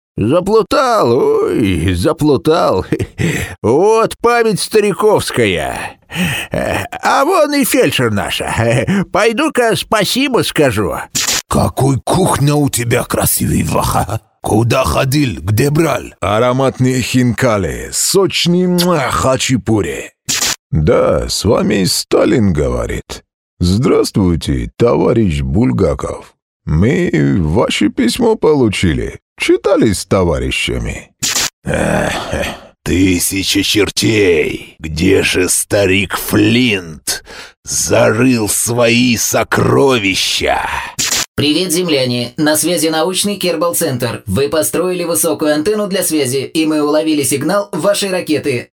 Обладаю харизматичным и уверенным баритоном. Озвучиваю рекламные ролики, компьютерные игры, оформление эфира, бизнес-презентации, IVR/автоответчики.
Профессиональная студия.
Тракт: микрофоны: Neumann TLM 103, ARK FET, звуковая карта: Apogee Duet 3, предусилитель: Avalon Design VT-747sp